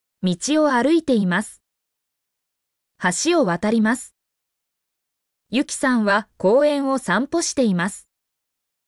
mp3-output-ttsfreedotcom-54_kv4OZsgI.mp3